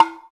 stompSignal.wav